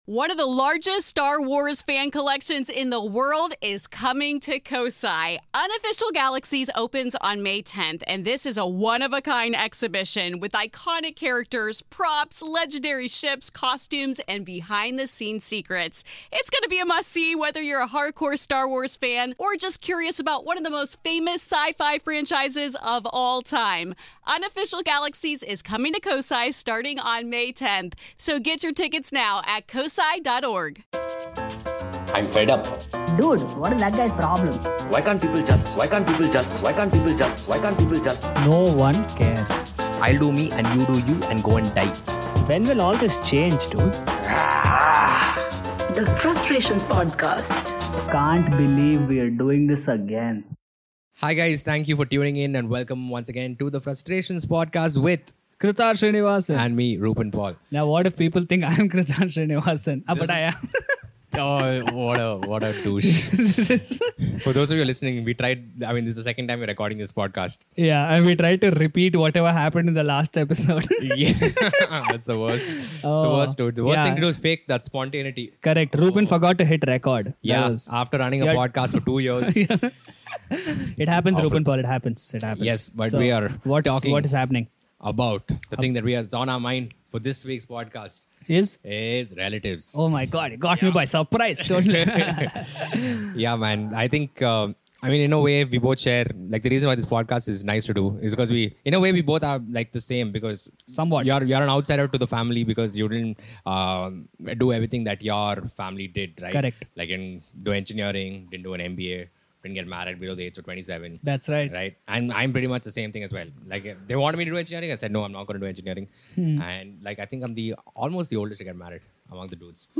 Listen to this episode on relatives, as these two comedians vent out many of their frustrations on the people that they are forced to put up with.